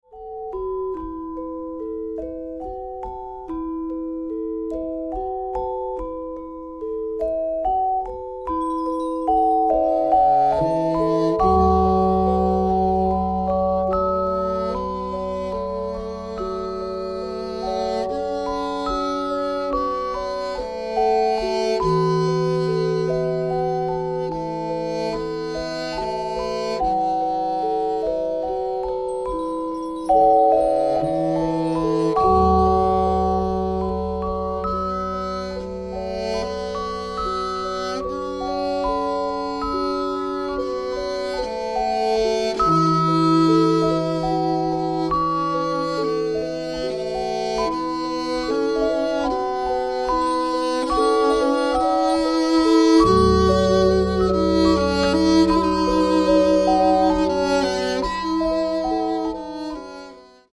Cello, gender, and gong
for cello and Indonesian percussion instruments